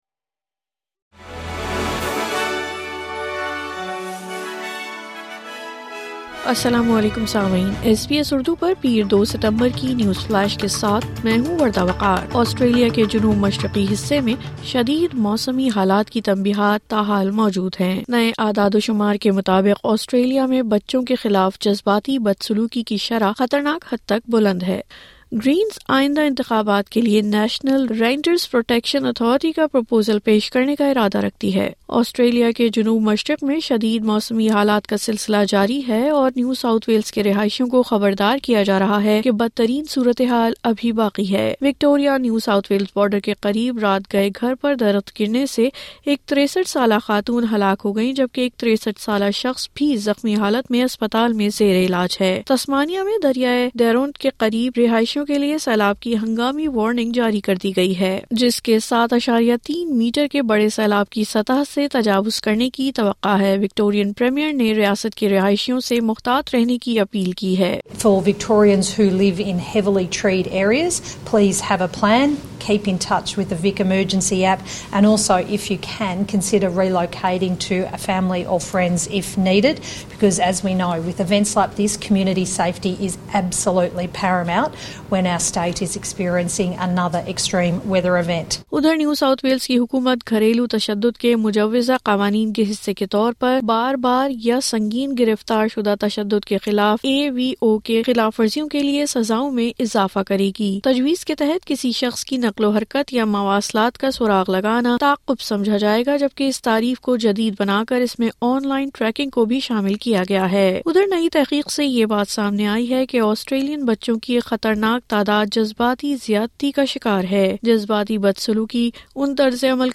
نیوز فلیش 02 ستمبر 2024: آسٹریلیا کے جنوب مشرقی حصے میں شدید موسمی حالات کی تنبہات ہنوز موجود ہیں